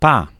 Ääntäminen
IPA: /pa/